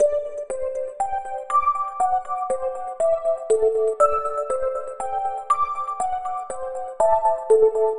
Night Chime 04.wav